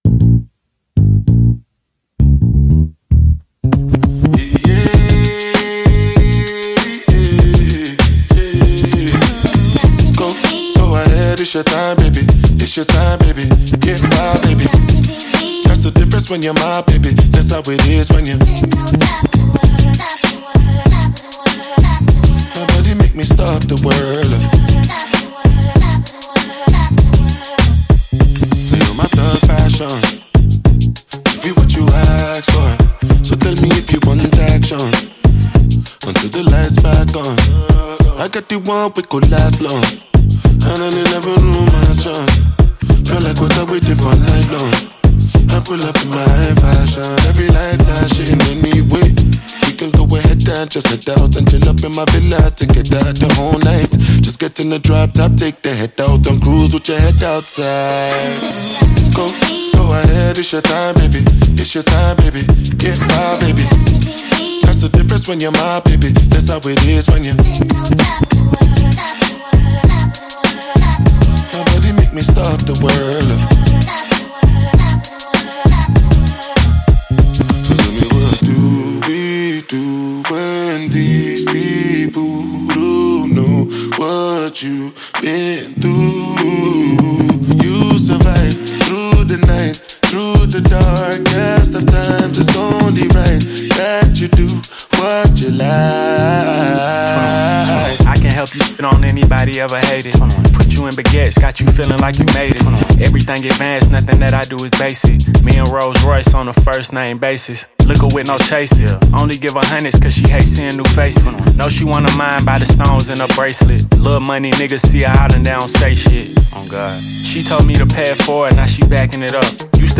This thrilling version has that energy to keep you going.